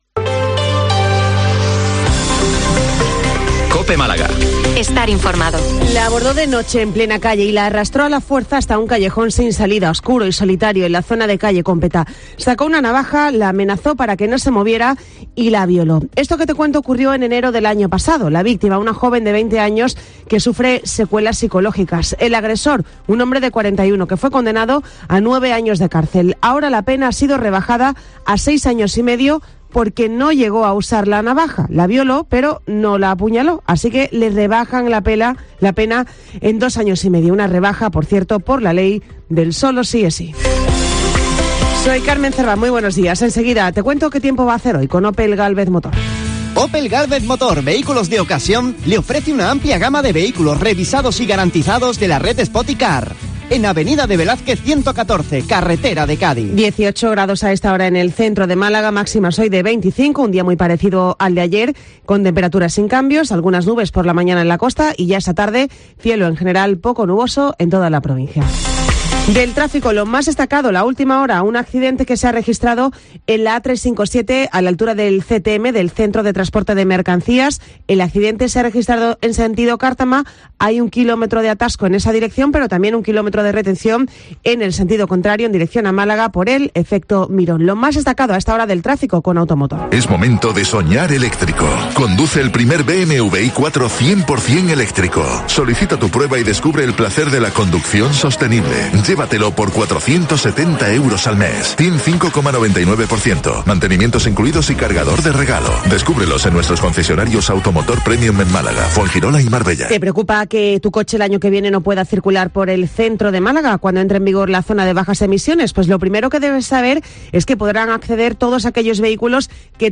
Informativo 08:24 Málaga-101023